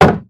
Minecraft Version Minecraft Version 25w18a Latest Release | Latest Snapshot 25w18a / assets / minecraft / sounds / item / shield / block1.ogg Compare With Compare With Latest Release | Latest Snapshot